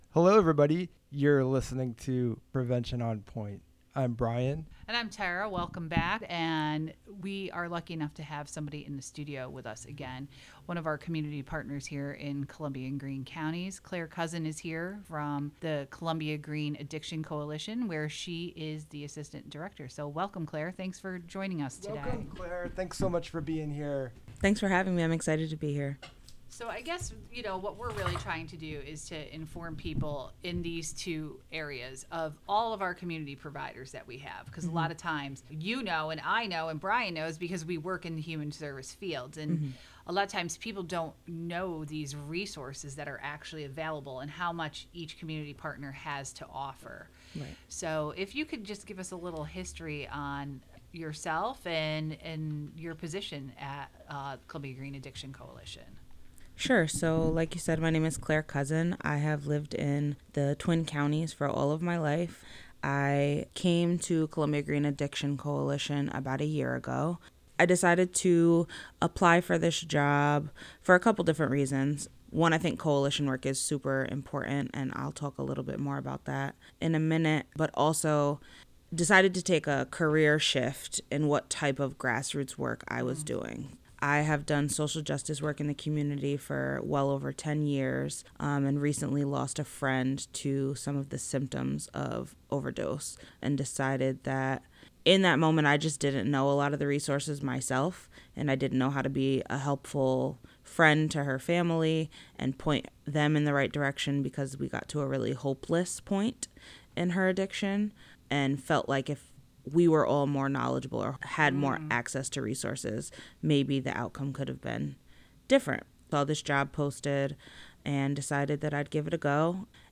Prevention on Point aims for lively conversation and useful information about substance use and misuse, prevention, wellness, and community.
and featuring different special guests each month from the vital world of community health and human services.